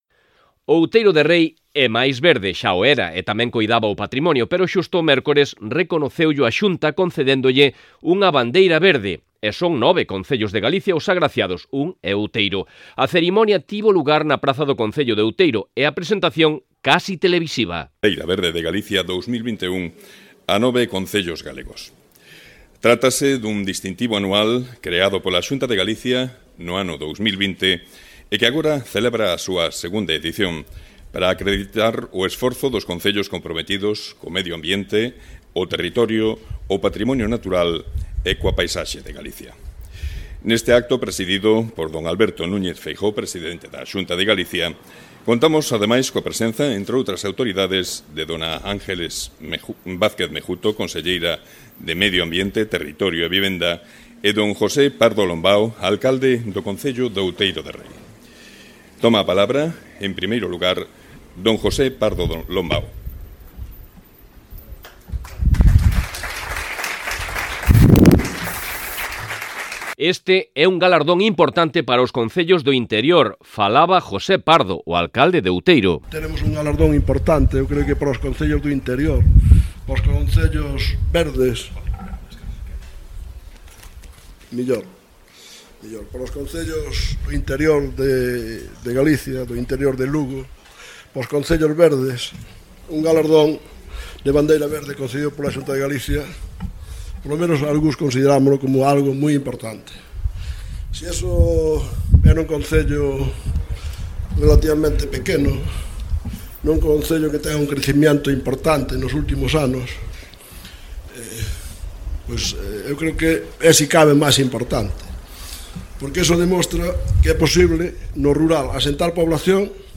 Este mércores tivo lugar na praza da vila de Outeiro de Rei o acto de entrega de Bandeiras Verdes de Galicia promovido pola Xunta de Galicia e das que foron merecedores os Concellos de Outeiro de Rei, Mondoñedo, Alfoz, Sober, Curtis, Moaña, Ordes, Parade de Sil e Porto do Son.
AUDIO-OUTEIRO-DE-REI-BANDEIRA-VERDE_REPORTAXE.mp3